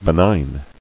[be·nign]